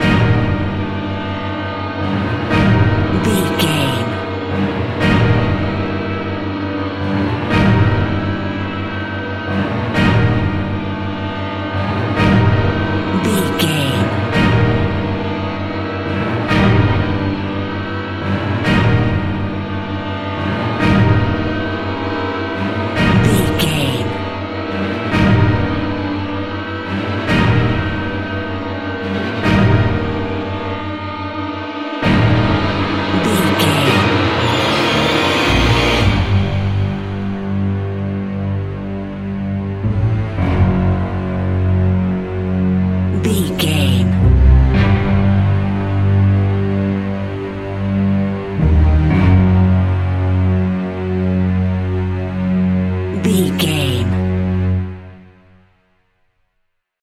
In-crescendo
Aeolian/Minor
ominous
suspense
haunting
eerie
strings
brass
percussion
violin
cello
double bass
cymbals
gongs
viola
french horn trumpet
taiko drums
timpani